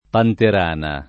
panterana [ panter # na ] s. f. (zool.)